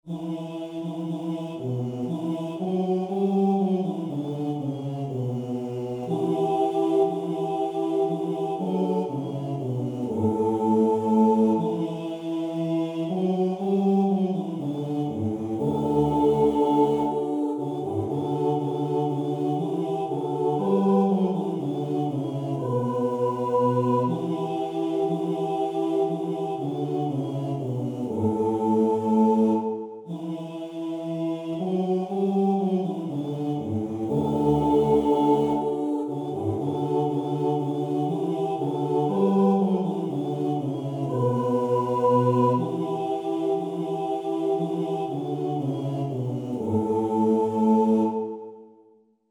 – Комп'ютерне відтворення нот: mp3.